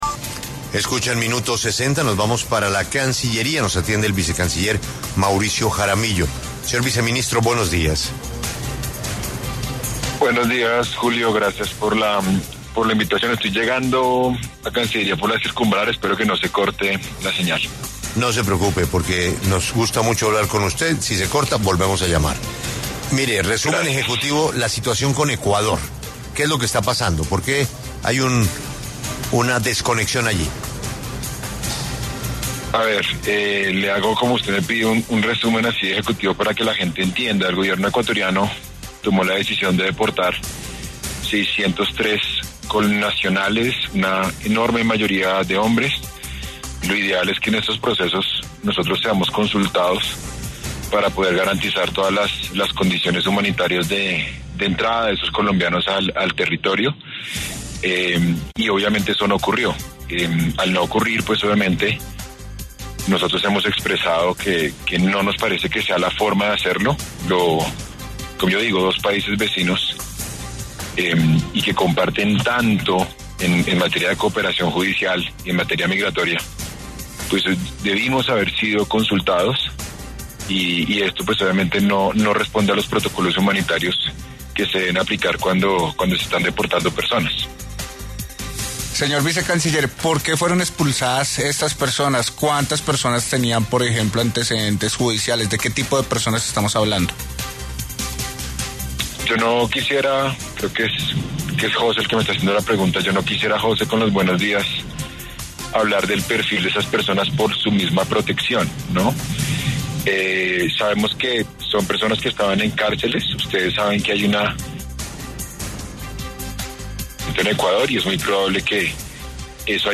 En diálogo con La W, el vicecanciller Mauricio Jaramillo se refirió a la situación de más de 600 colombianos que fueron deportados desde Ecuador, según el Gobierno nacional, sin ningún tipo de protocolo.
Vicecanciller-Jaramillo-habla-en-La-W-sobre-las-relaciones-de-Colombia-con-Ecuador-y-Venezuela-1.mp3